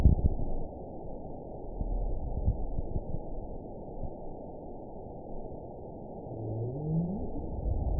event 920735 date 04/07/24 time 02:52:03 GMT (1 year, 1 month ago) score 8.40 location TSS-AB03 detected by nrw target species NRW annotations +NRW Spectrogram: Frequency (kHz) vs. Time (s) audio not available .wav